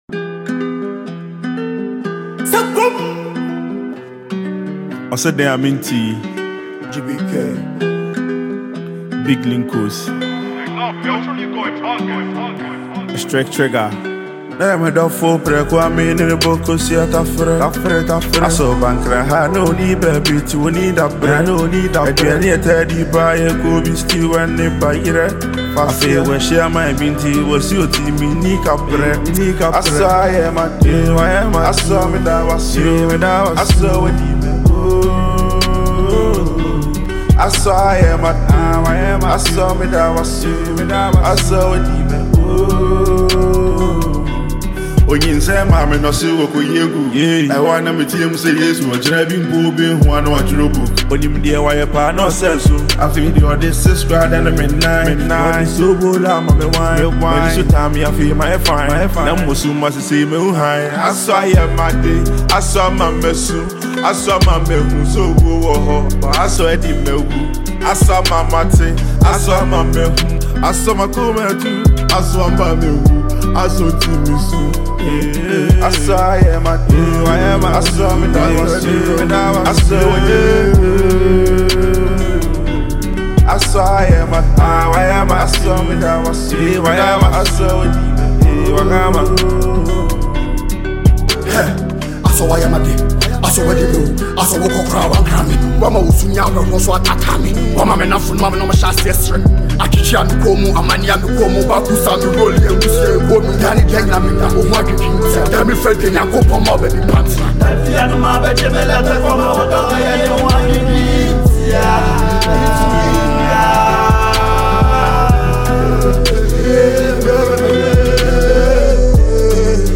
a Ghanaian trapper
Enjoy this well-produced asakaa tune.